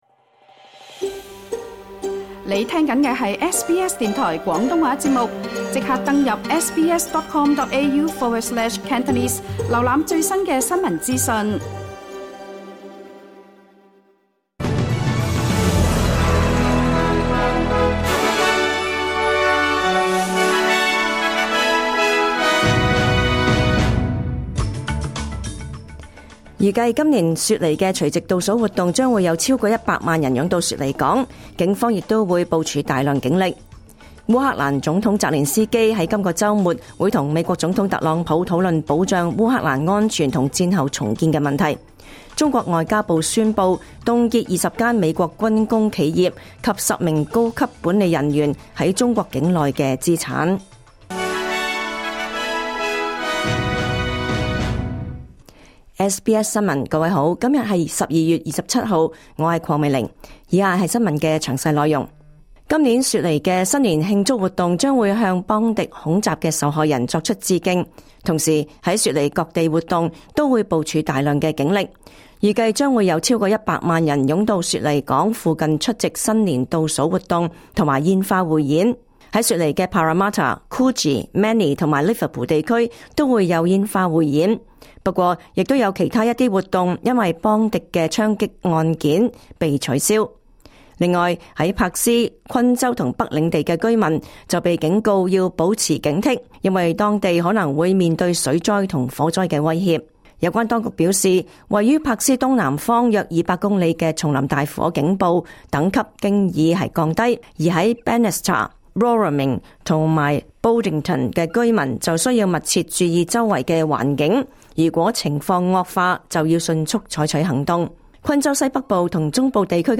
2025 年 12 月 27 日 SBS 廣東話節目詳盡早晨新聞報道。